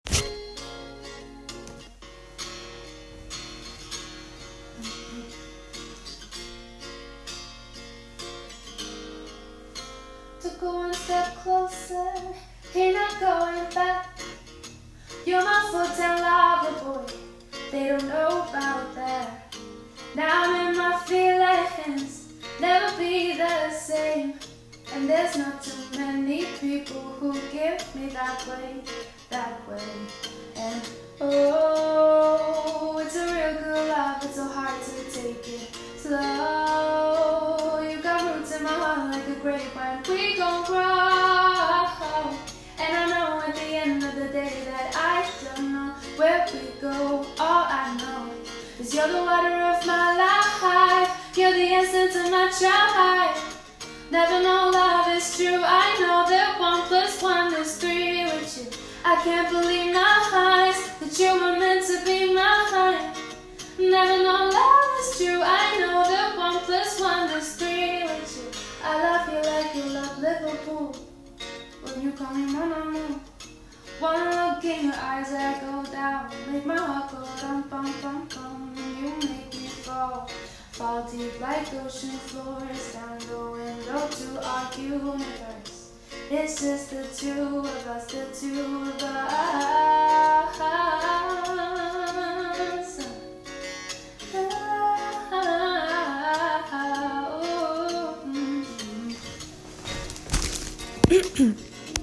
Wenn dir meine Stimme (hört man in der Audio-Aufnahme) gefällt, melde dich doch gerne mit ein paar Infos über dich und deinen eigenen Wünschen und Vorstellungen.